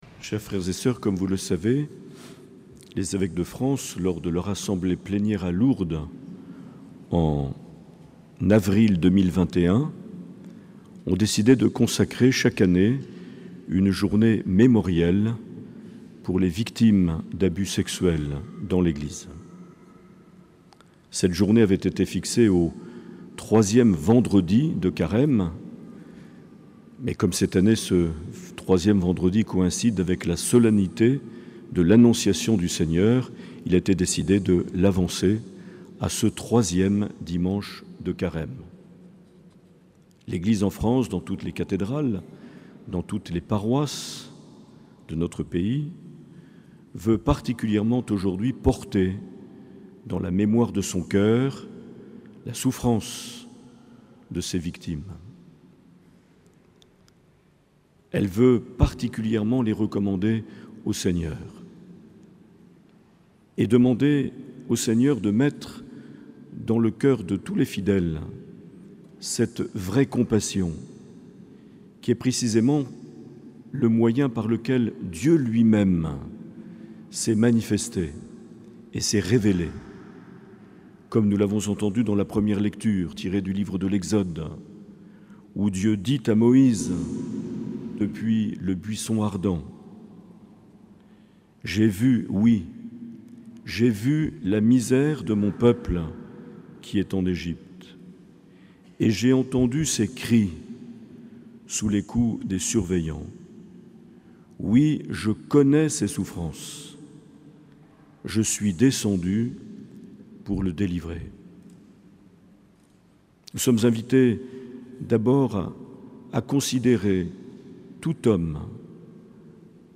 Homélie de Mgr Marc Aillet le 20 mars 2022 en la cathédrale sainte Marie de Bayonne.